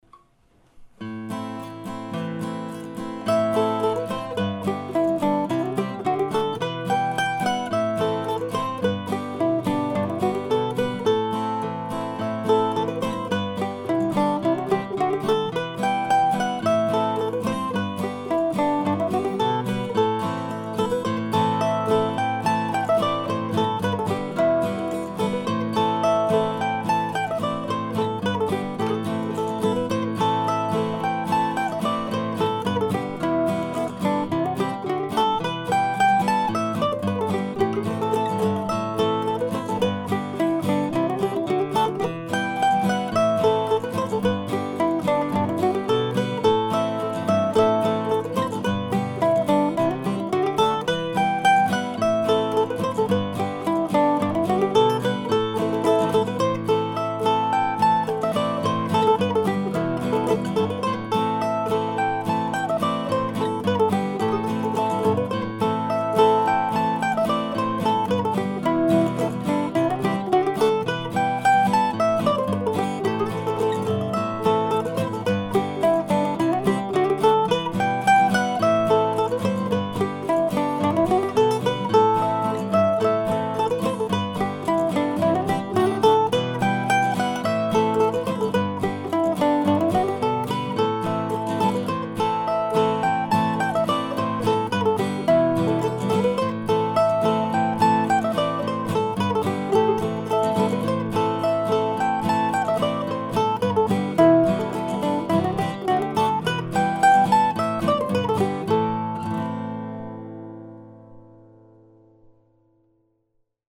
Shepherd's Purse (mp3 ) ( pdf ) A tune from March 2009, vaguely Scottish.